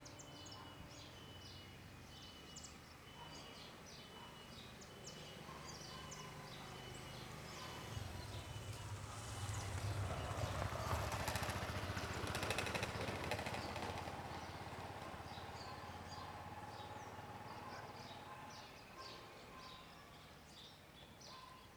Moto passando devagar em parque a tarde com cigarras e cachorro Cachorro , Cigarra , Dia , Moto , Parque ecológico , Tarde Brasília , Parque Olhos D'água Surround 5.1
CSC-14-065-LE - Moto passando devagar em parque a tarde com cigarras e cachorro.wav